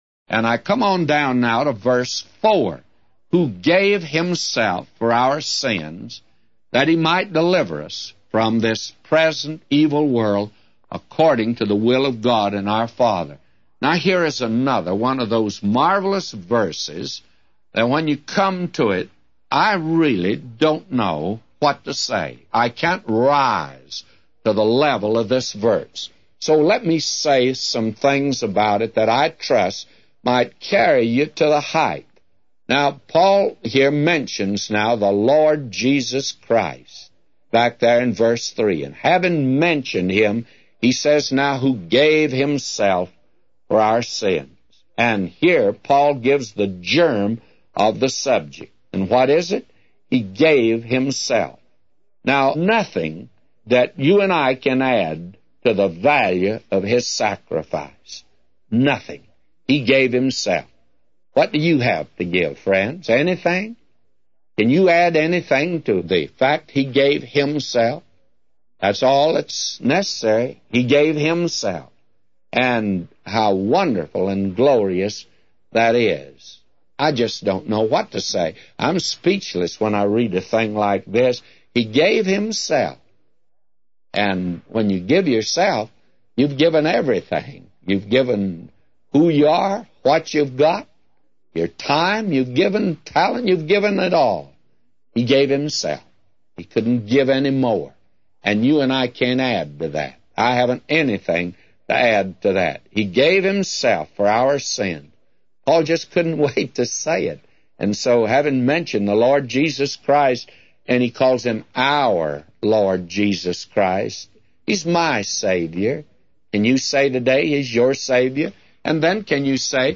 A Commentary By J Vernon MCgee For Galatians 1:4-999